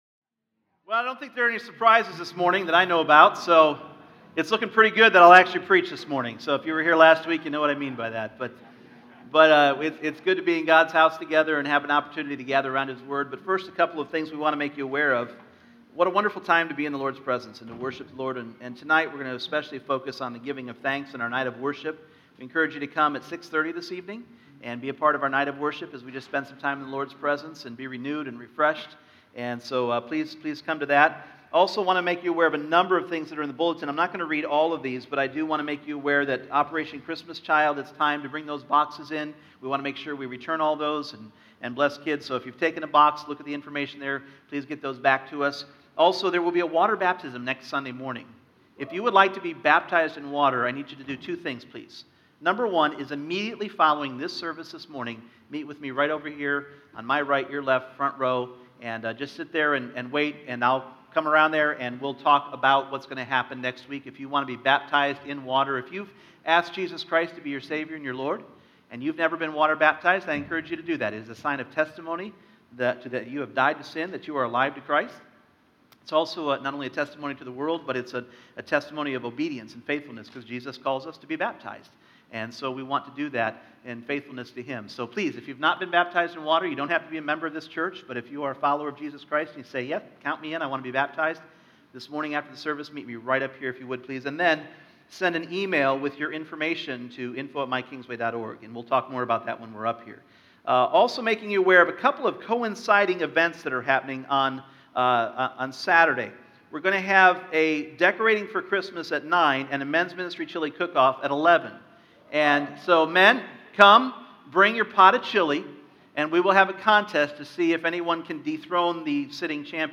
Individual Messages Service Type: Sunday Morning Gratitude is a powerful spiritual tool.